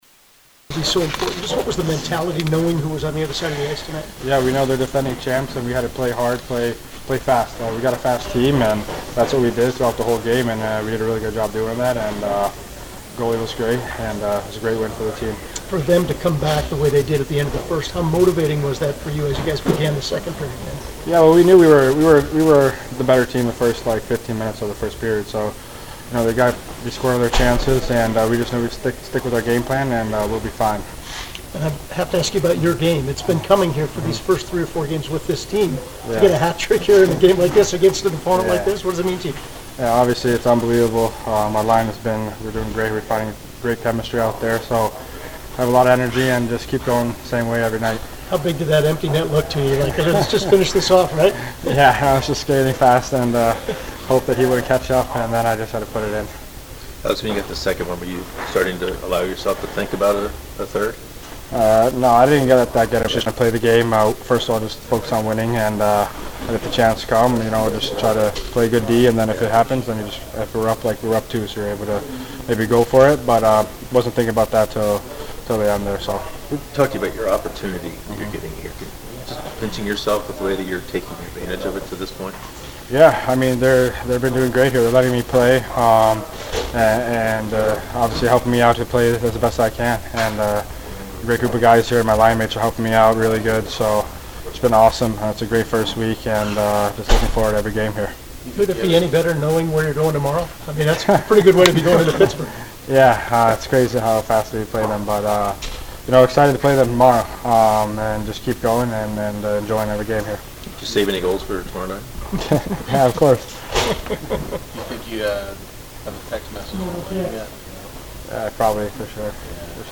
Alexander Nylander CBJ LW 50th Hat Trick in club history. Postgame Locker Room Press Conference CBJ 6 Vegas 3 Monday March 4, 2024.mp3